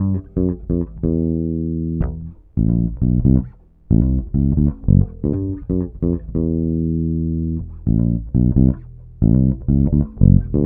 Bass 30.wav